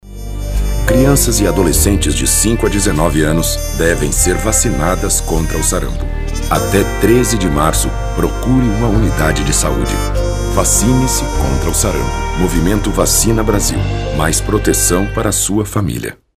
Masculino
Voz Padrão - Grave 00:16
Voz grave coloquial.